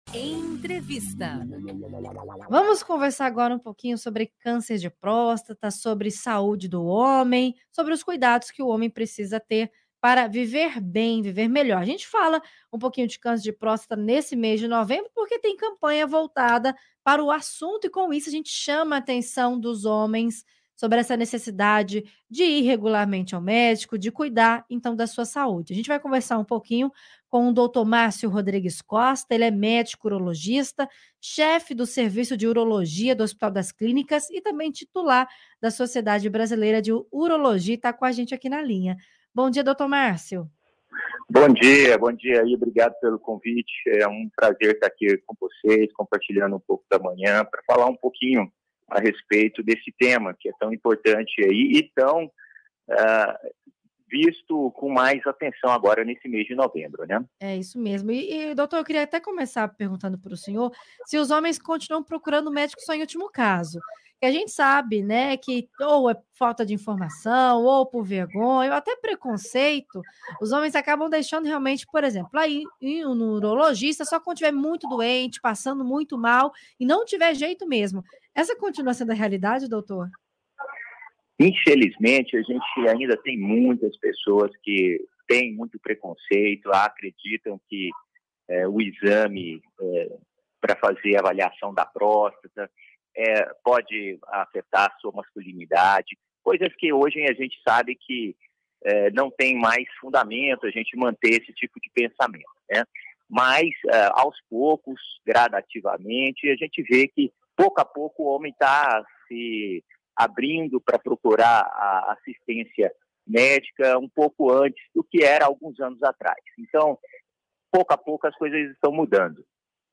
Entrevista Rádio Difusora - Câncer de Próstata